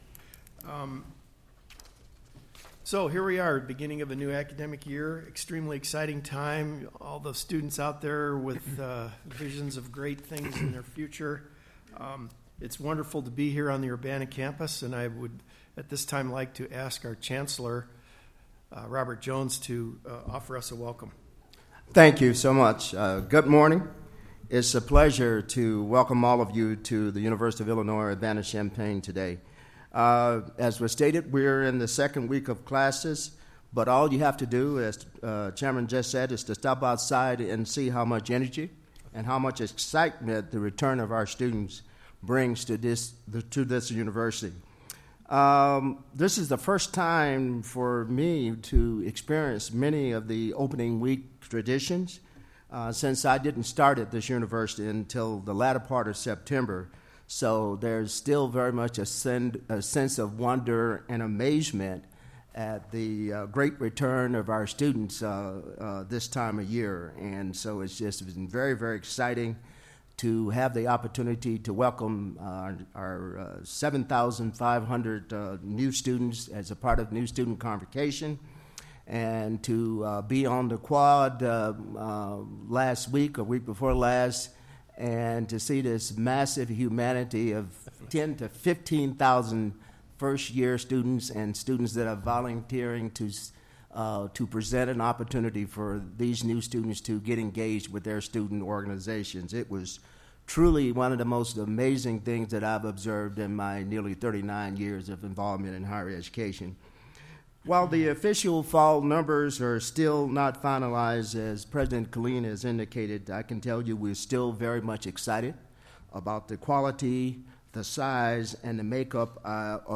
Board Meeting Audio Recording: September 7, 2017